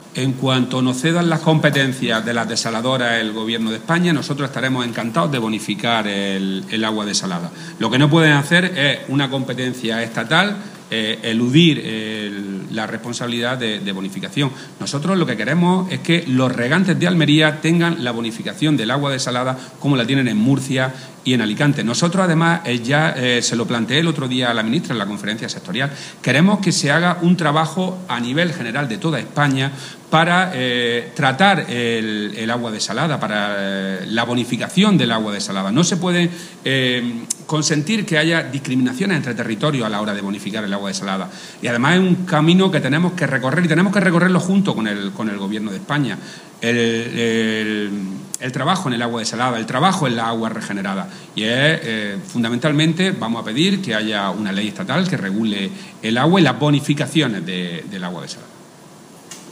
Declaraciones de Rodrigo Sánchez sobre la bonificación del agua desalada